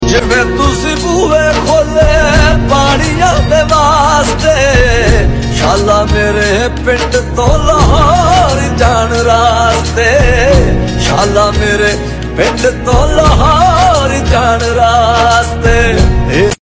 دریائے چناب میں پانی کا sound effects free download